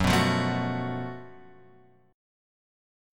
F# Minor Major 7th Double Flat 5th